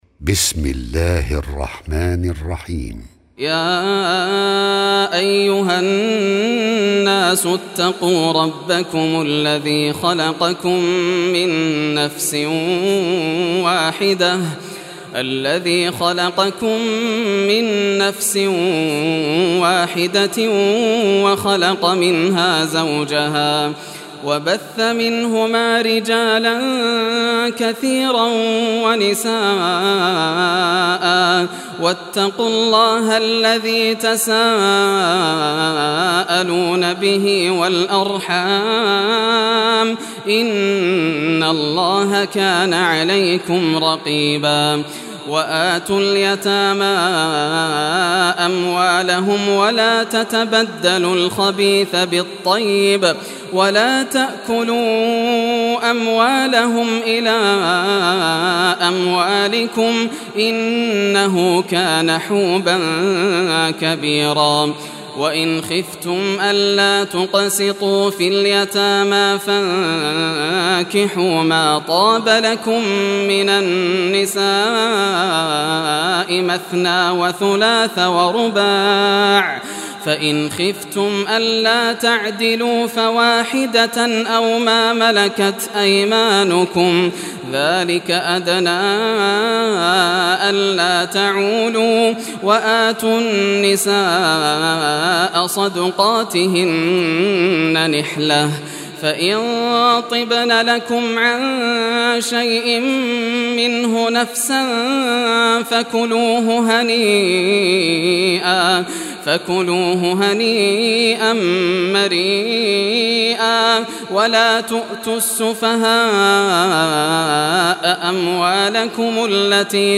Surah An-Nisa, listen or play online mp3 tilawat / recitation in Arabic in the beautiful voice of Sheikh Yasser al Dosari.